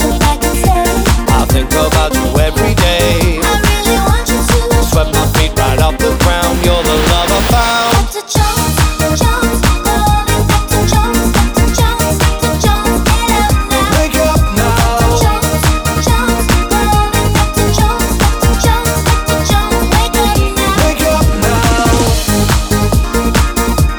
Duet Version Duets 3:20 Buy £1.50